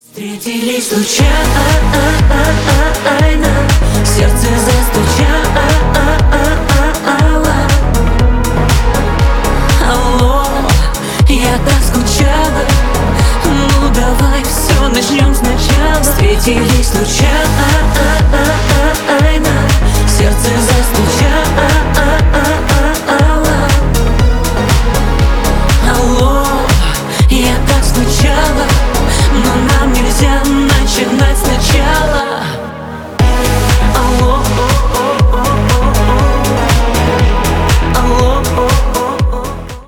Жанр: Русская поп-музыка / Русские